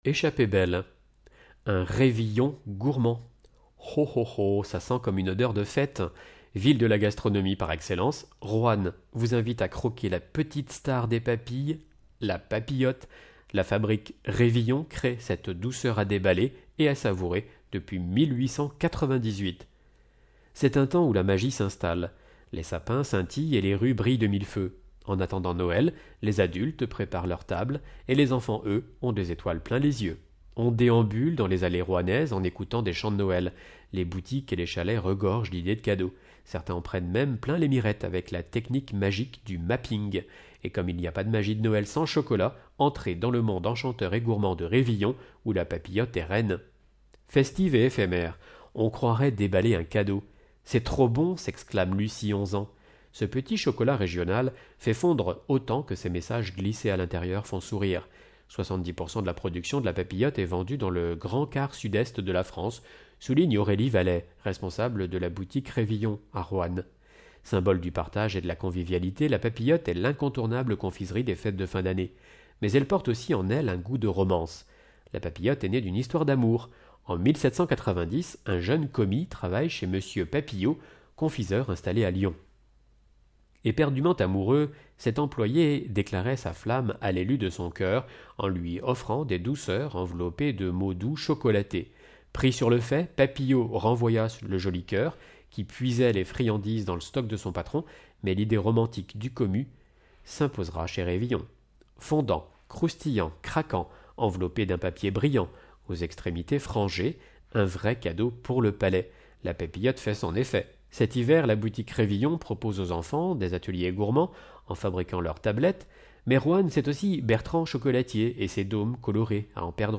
Loire Magazine n°144 version sonore